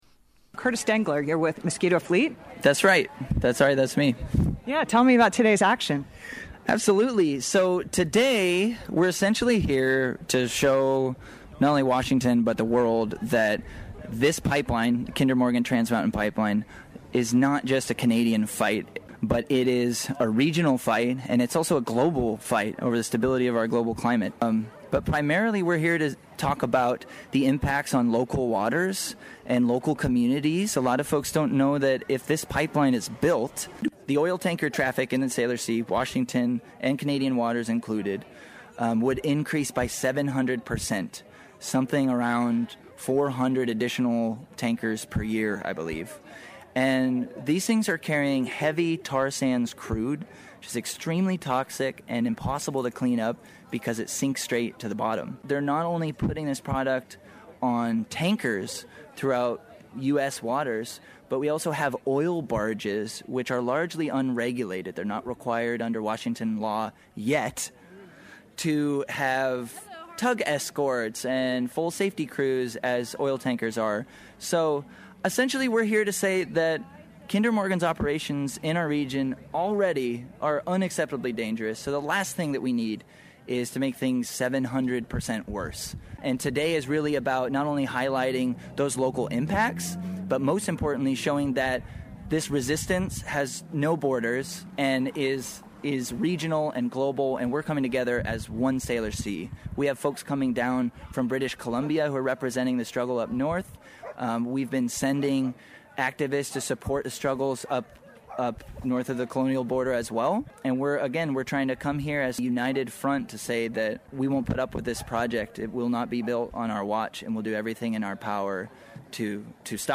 speaks with canoe families and kayaktivists who were in Seattle on May 20 for an Indigenous-led day of resistance to the Kinder Morgan Trans Mountain pipeline